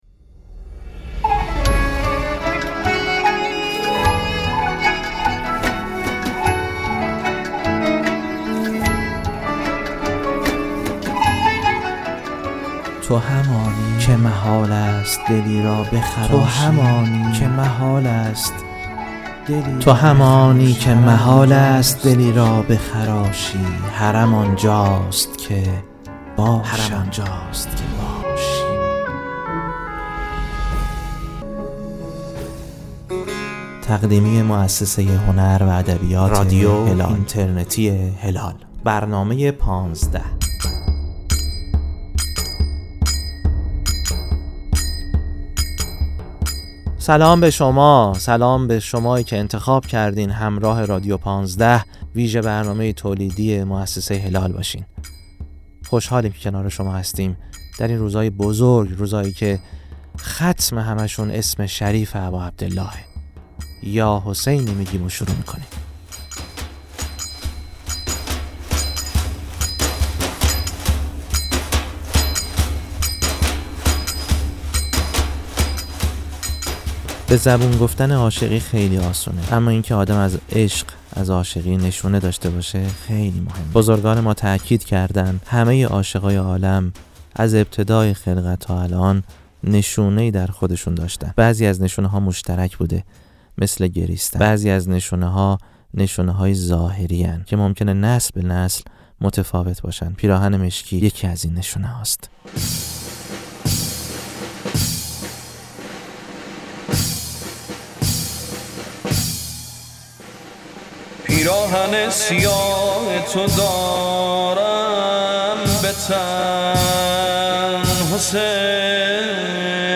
این قسمت، زمزمه‌هایی‌ است برای دل‌هایی که هنوز نرفته، اما از رفتن پشیمان نیستند. با مداحی، سخن و نجوای کتاب‌هایی که بوی تربت دارند، قسمت سوم مجموعه «پانزده» را بشنوید؛ صدای راه است، صدای دلتنگی... صدای جاده‌ اربعین.